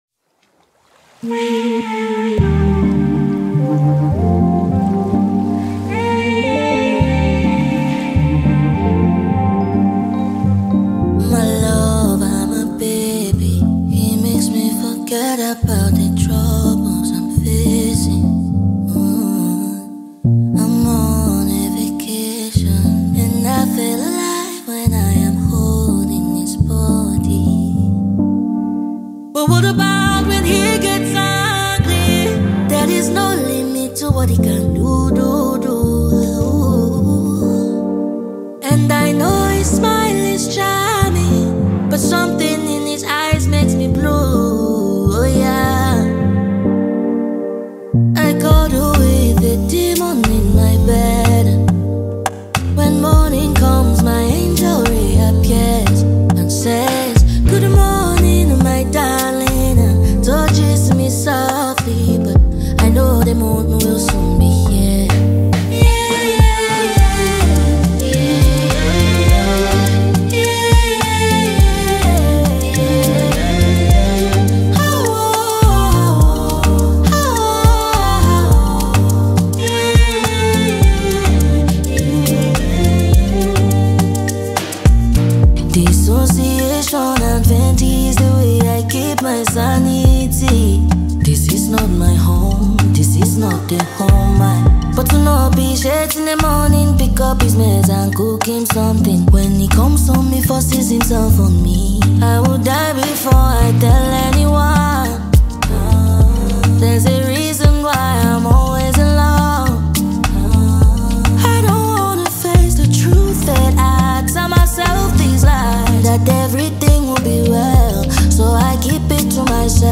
Prominent Cameroonian-American songstress and performer